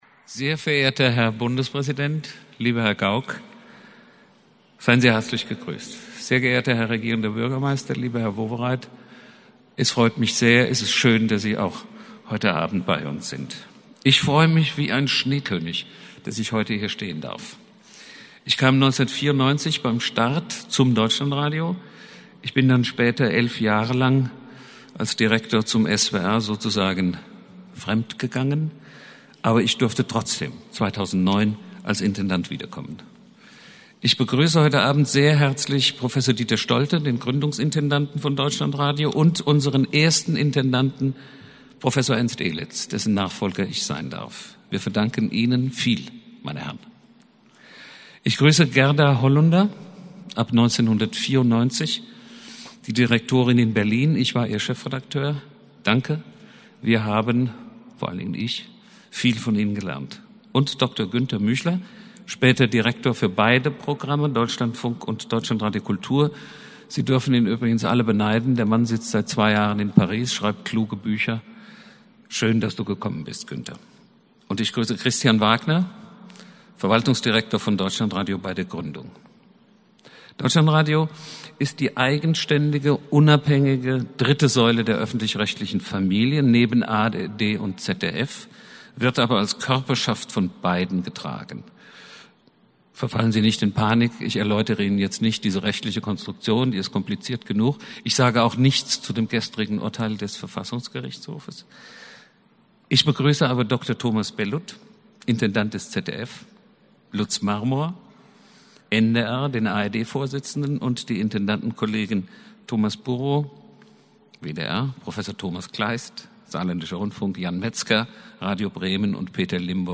Was: Festakt „20 Jahre DeutschlandRadio“
Wo: Berlin, Kommunikationsmuseum